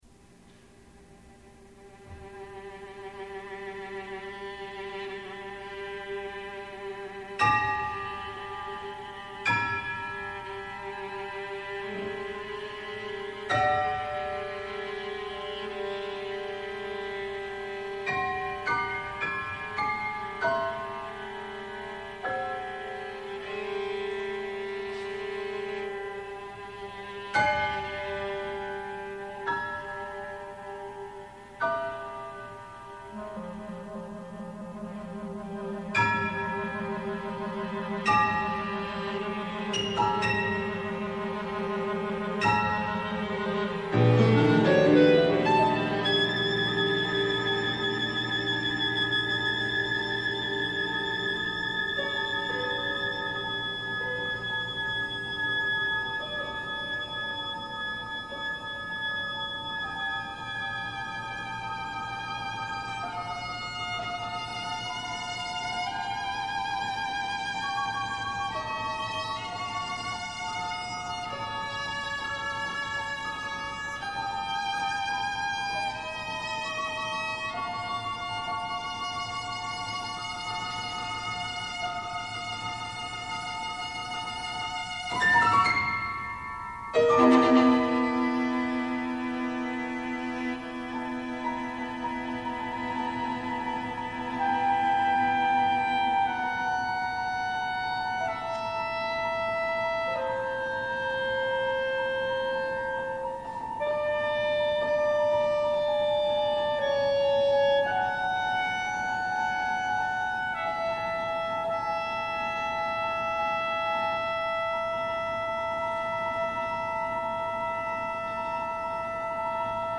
clarinet, viola and piano